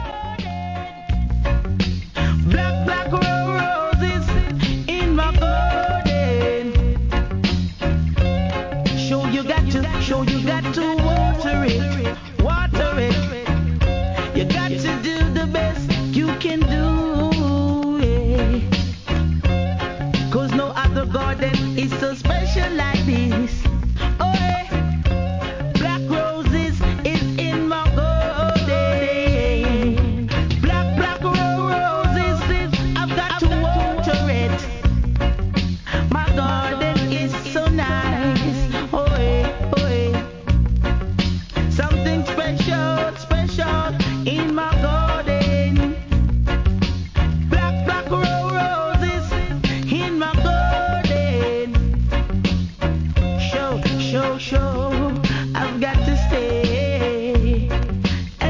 終盤周期的なノイズ。
REGGAE